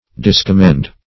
Discommend \Dis`com*mend"\, v. t.